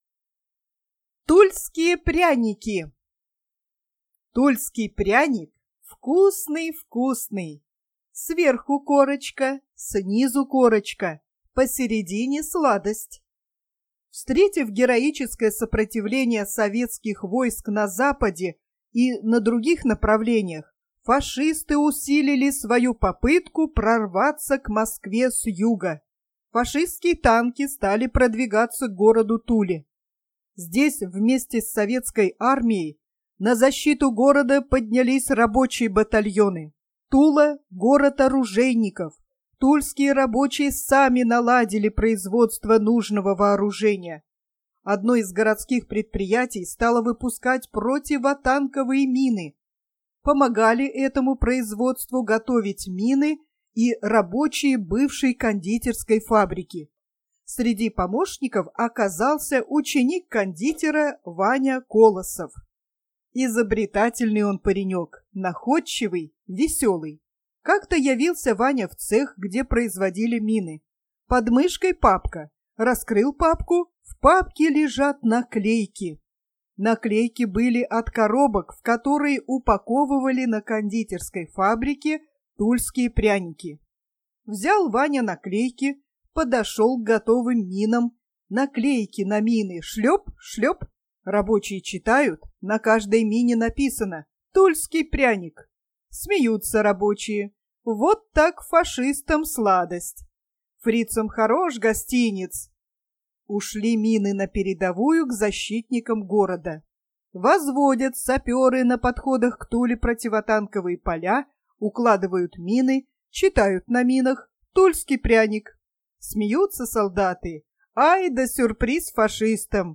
Аудио рассказ "Тульские пряники" детского писателя Сергея Петровича Алексеева из его книги "Рассказы о Великой Отечественной войне".